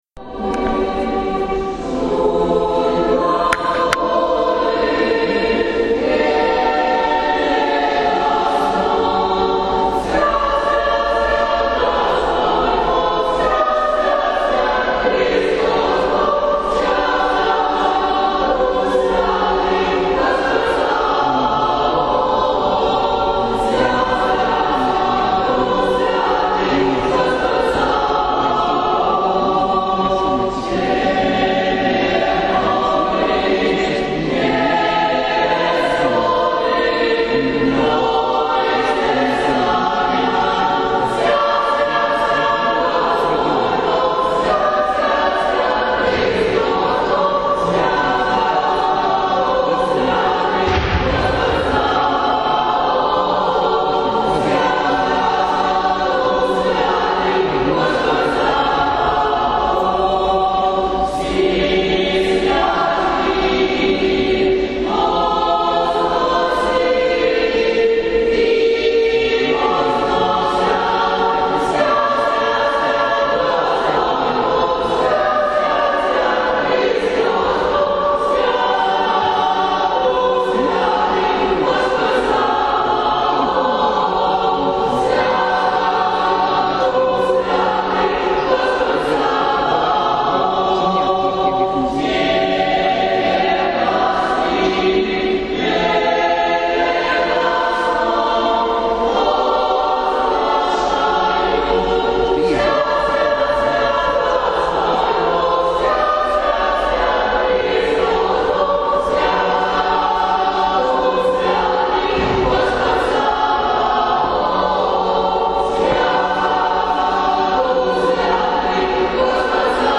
В Успенском кафедральном соборе Венгерской епархии
Пел хор
Песнопения на Литургии в день прп. Сергия Радонежского:
Песнопения-на-Литургии-в-день-прп.-Сергия-Радонежского.mp3